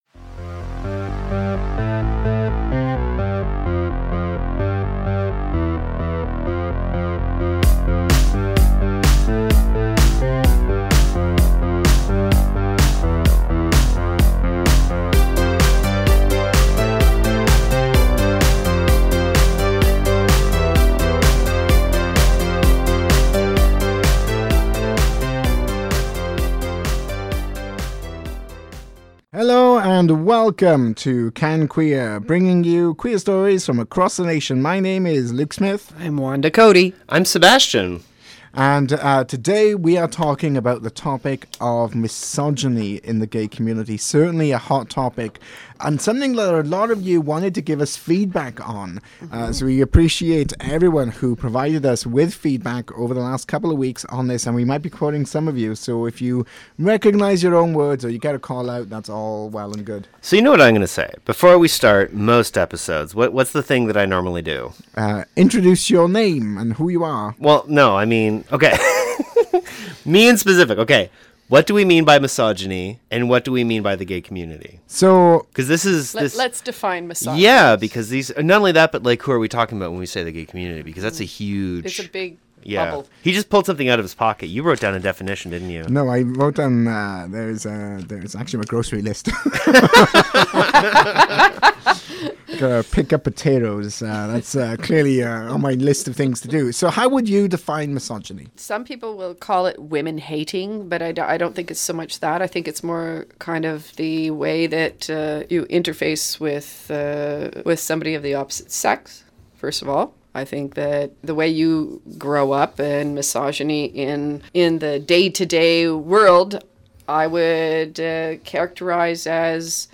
We dive into the question are gay men misogynist in this wide ranging conversation